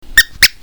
cartoon25.mp3